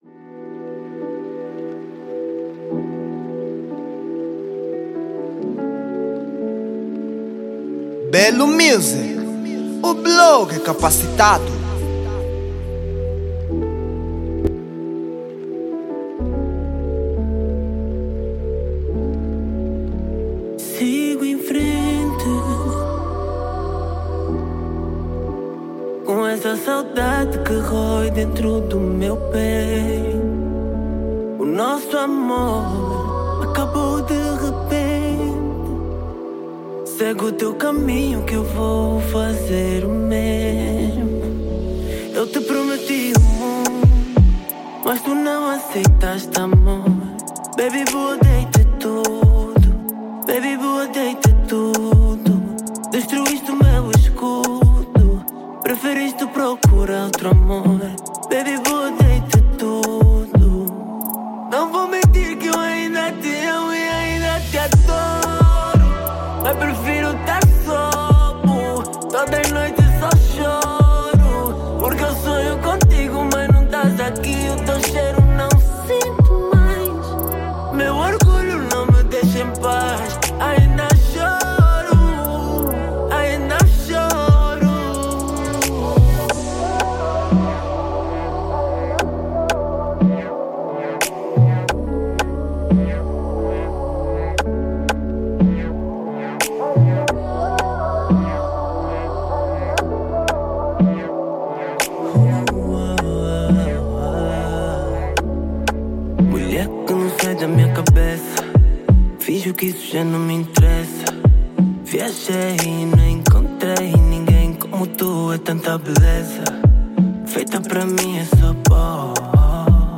Género: Kizomba